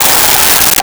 Tire Screech
Tire Screech.wav